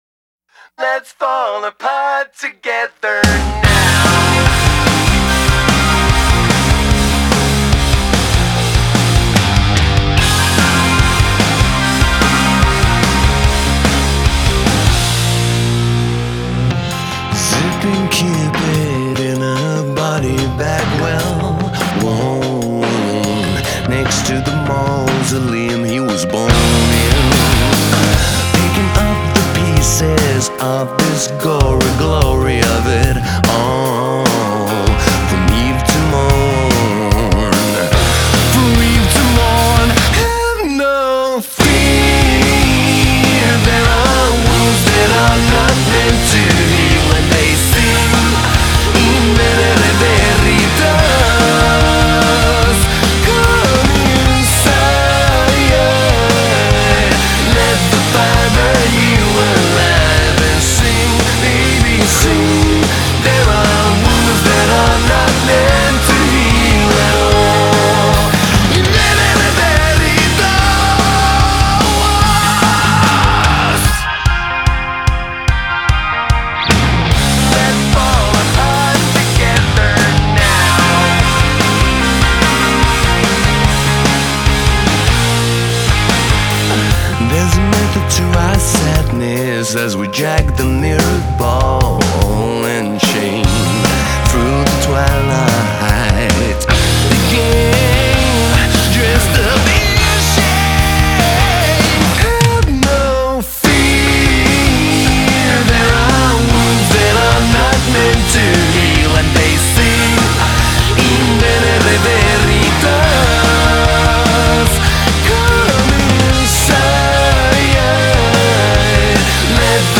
solo vocalist